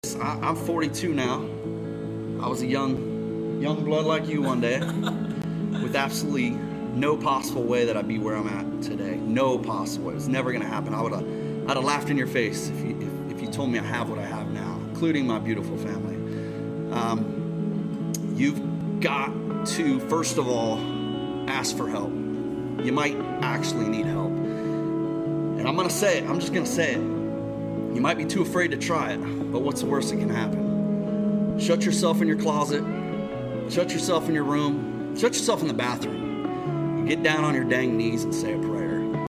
Roman Atwood gave a motivational speech on Kai Cenat mafiathon